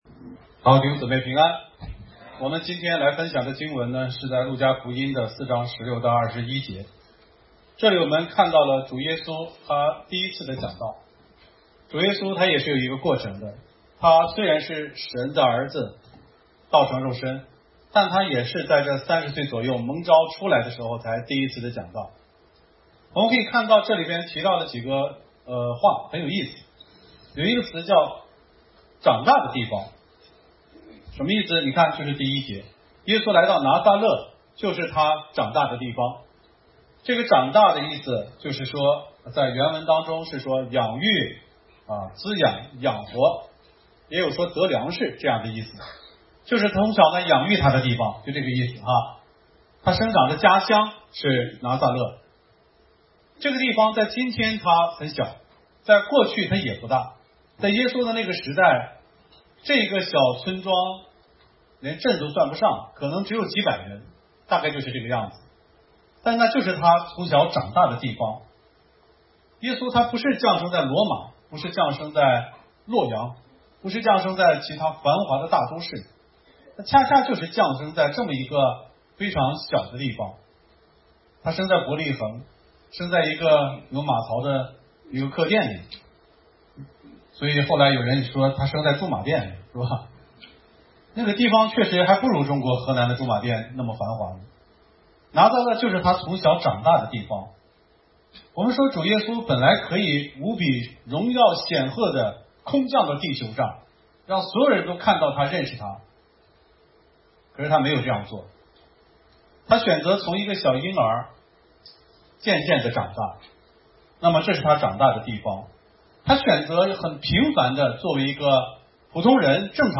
首页 讲章 正文 天国斩杀线？